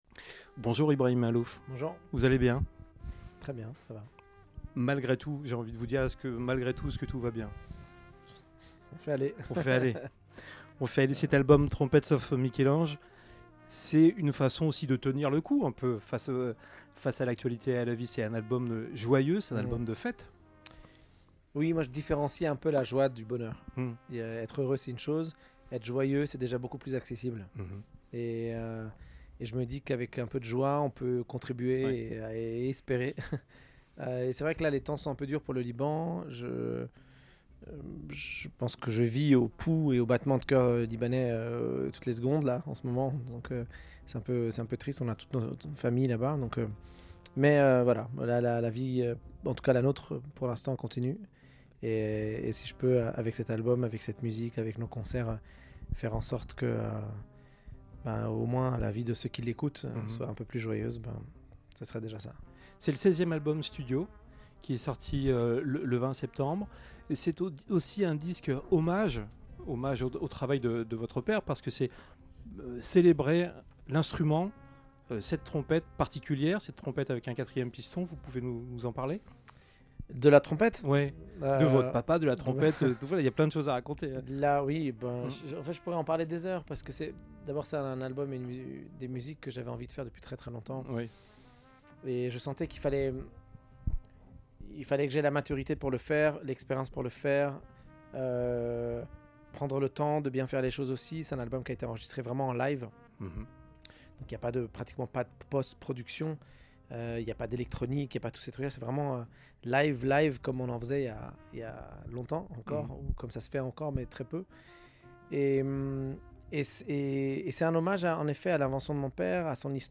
Interview Jazz Radio